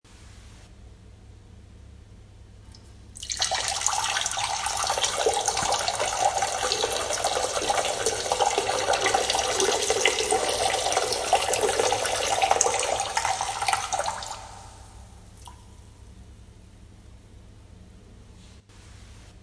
Download Peeing sound effect for free.
Peeing